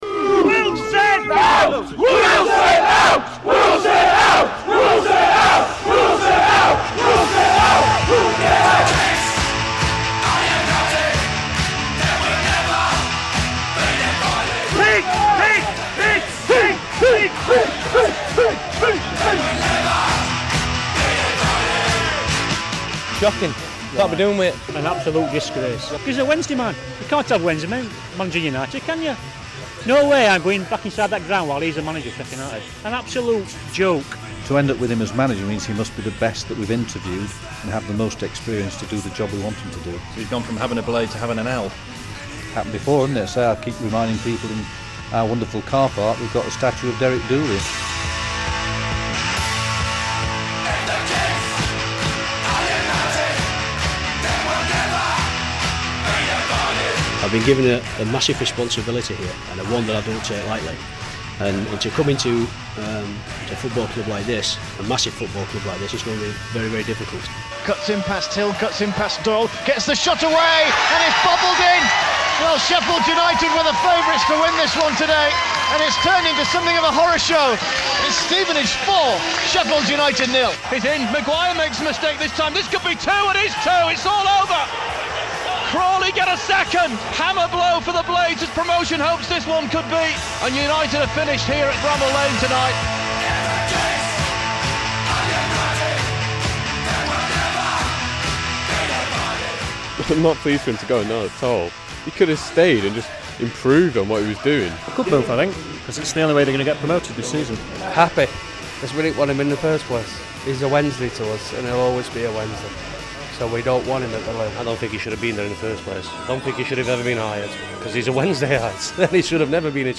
MONTAGE: He's been United manager for 2 years.